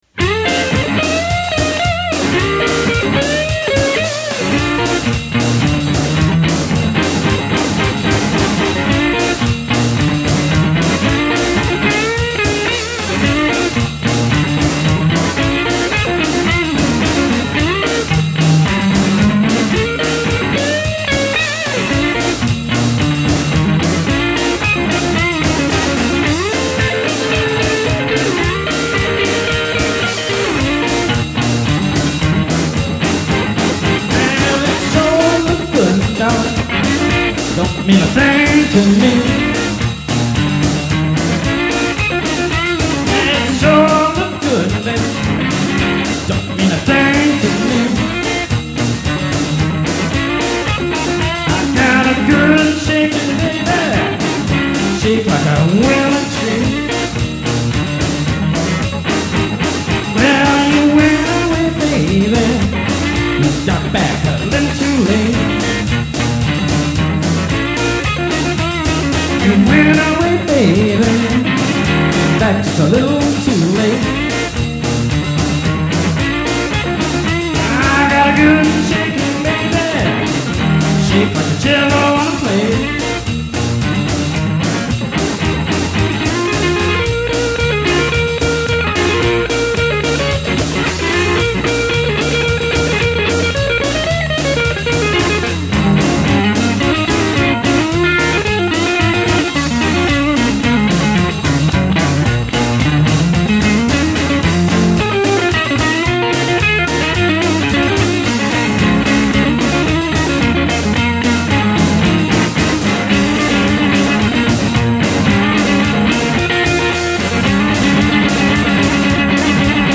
From our demo tape